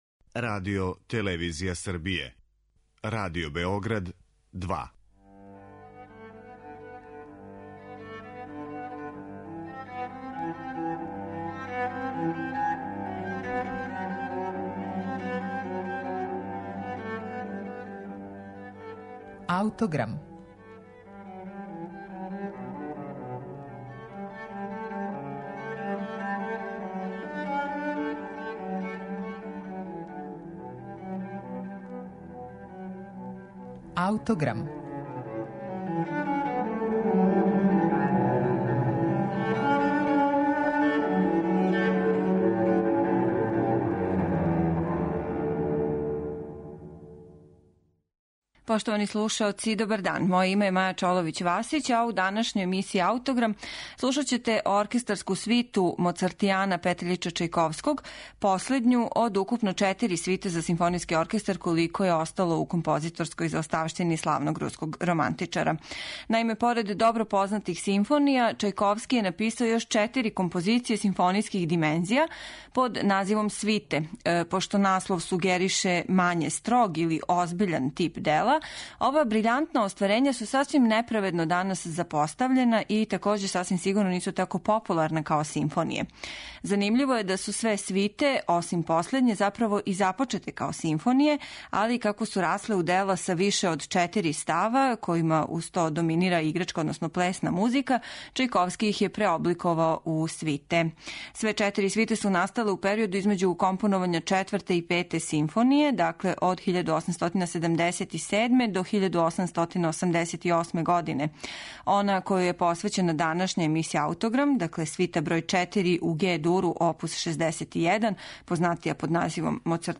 за симфонијски оркестар
у Ге-дуру